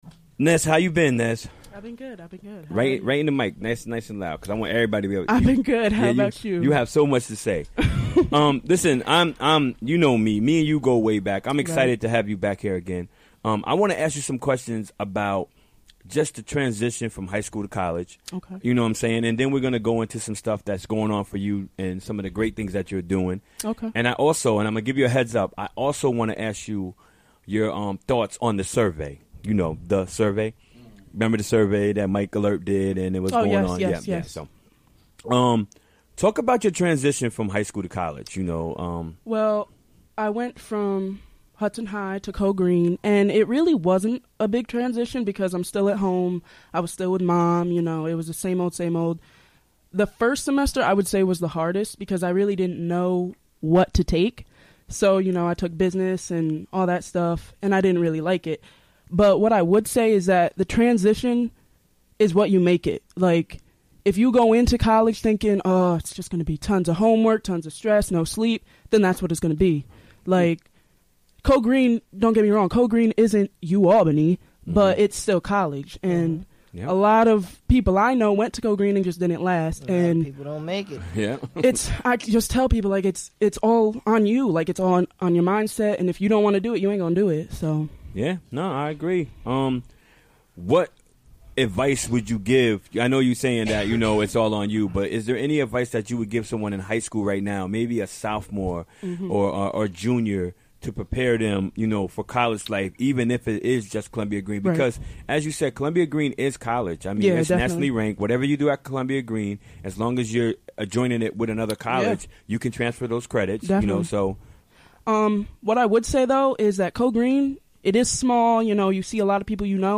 Recorded during the WGXC Afternoon Show Wednesday, March 22, 2017.